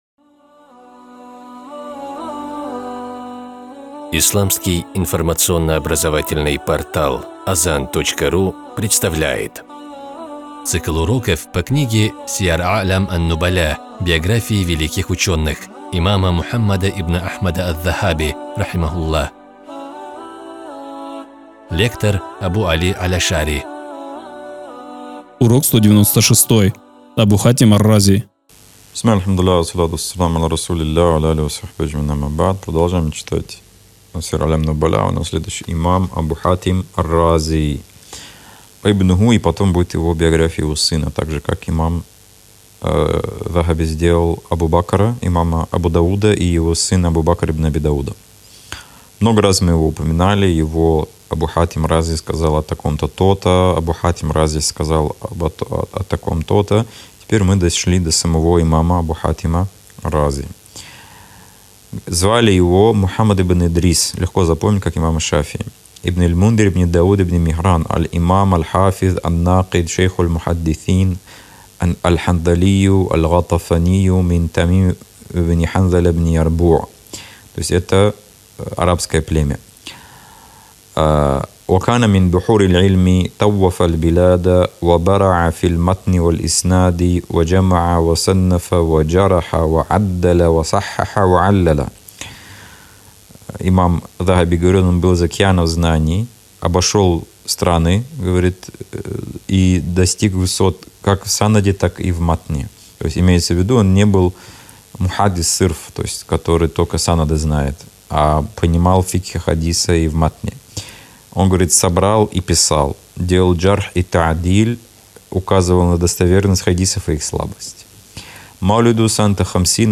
Цикл уроков по книге великого имама Аз-Захаби «Сияр а’лям ан-нубаля».